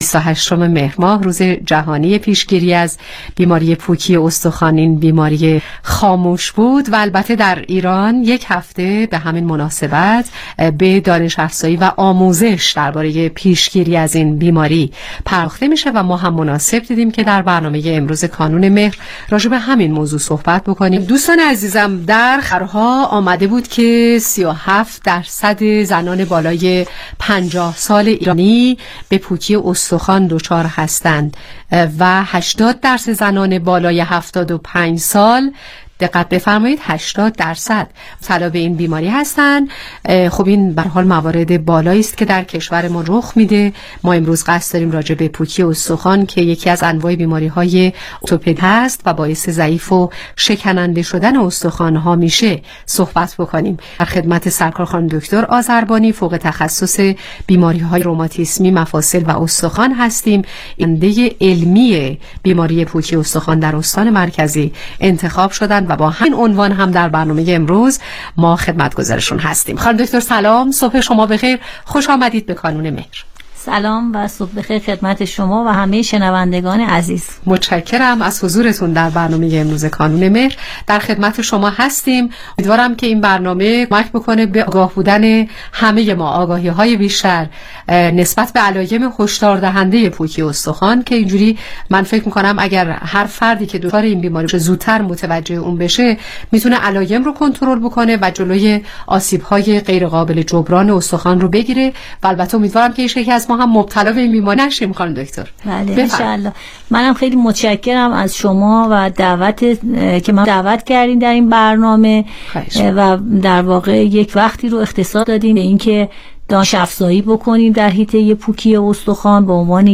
برنامه رادیویی کانون مهر